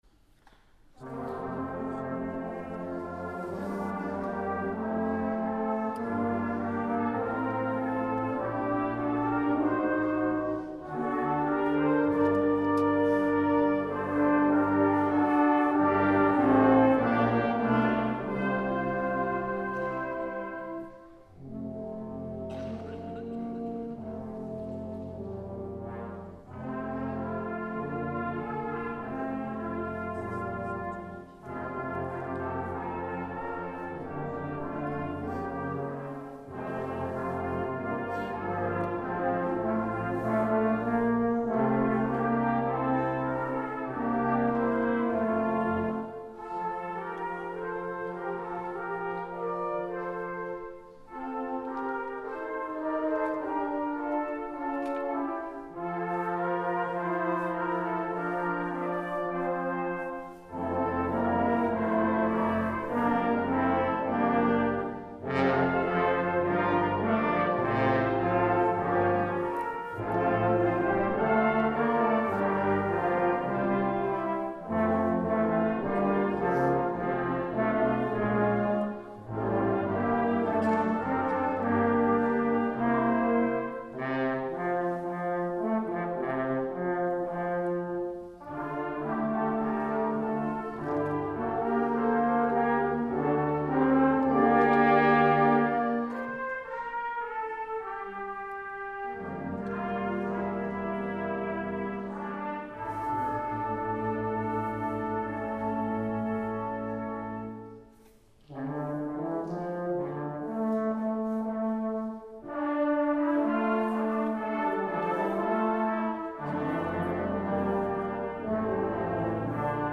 Posaunenchor Düsseldorf-Garath
Advents-Konzert in der DBK Garath mit lieblichen Tönen von Petit-Noel-en-France